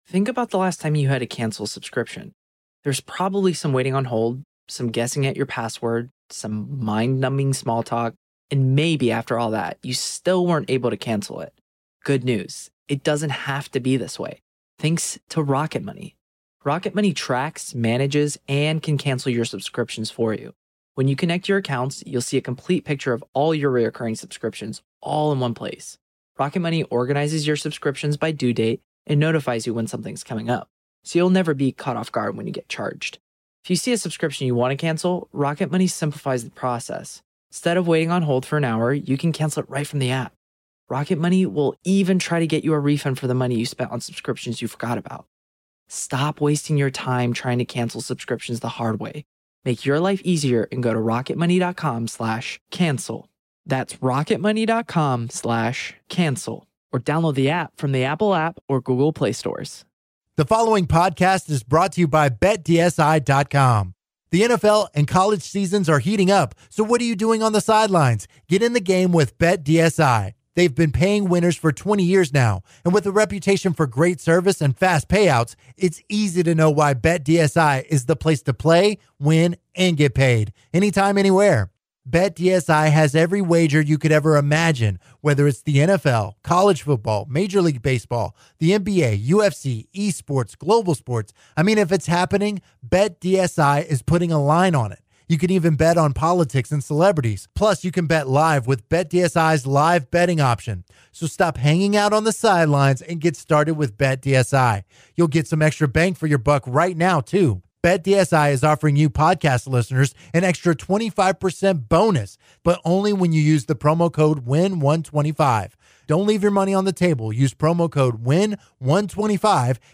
The second hour of the show starts off with some college football talk focusing on the Big 12, as well as Ohio State. The guys then go on to discuss the pro game and which coaches in the NFL are in the hot seat and pivot back to the LSU/A&M game as people call in commenting on the action. And Mack Brown has a new job and will be calling in at 9:30! Plus, Kliff Kingsbury is in need of a job, would he make a good pro-football assistant or should he look for another college head coaching job?